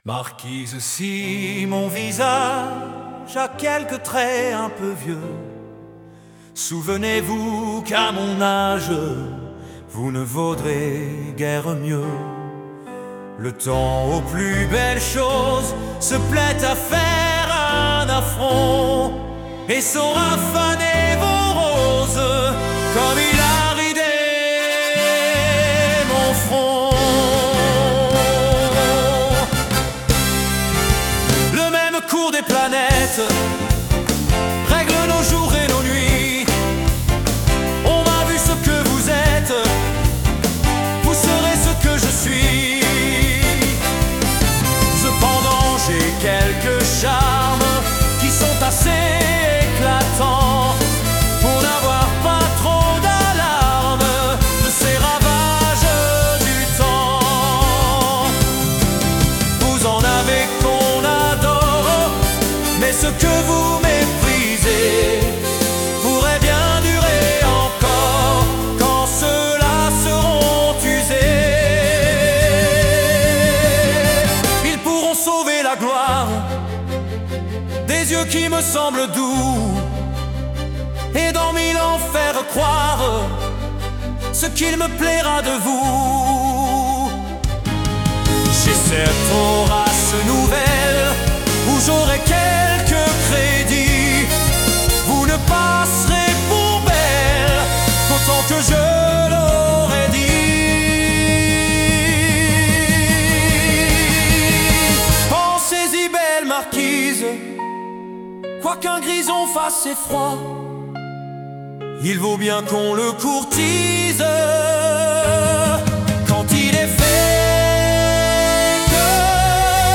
La musique moderne et la poésie ancienne s'entremêlent